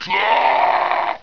death2.wav